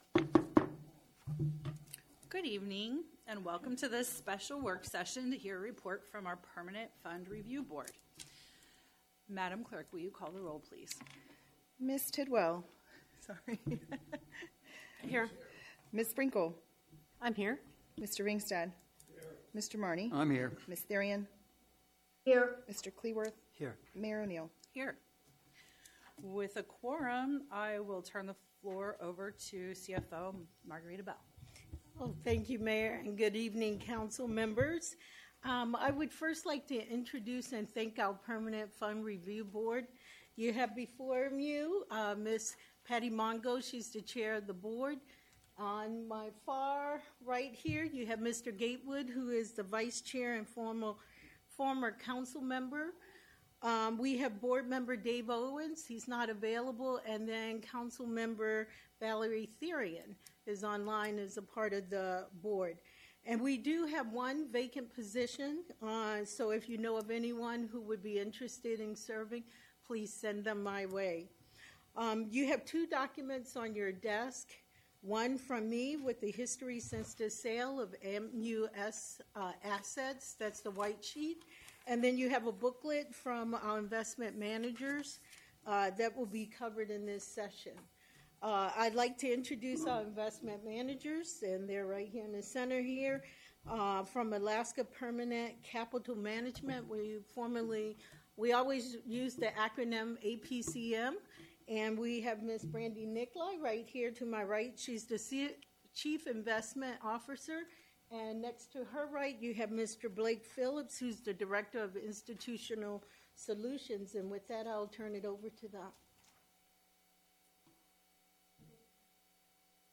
Special City Council Work Session - Permanent Fund Review Board Annual Report - January 26, 2026 | Fairbanks, Alaska